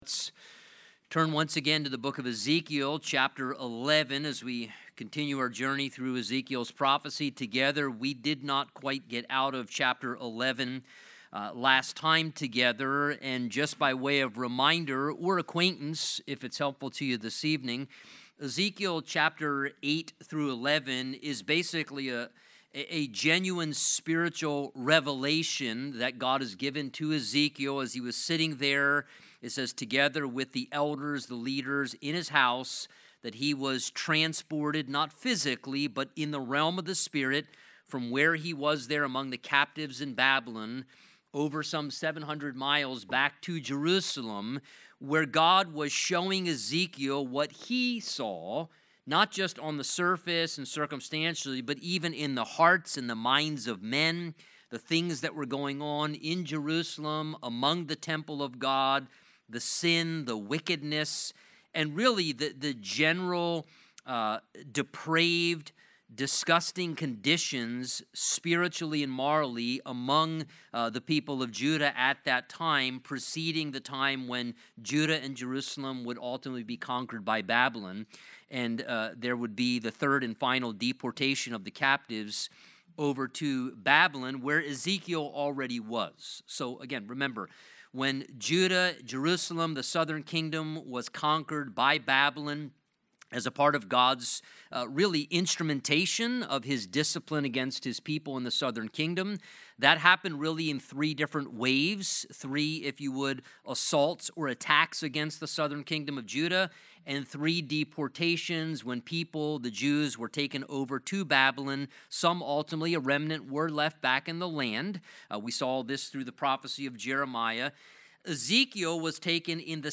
Midweek services